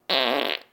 R09_0034-bathroom noise
bathroom breaking fart gas noise wind sound effect free sound royalty free Memes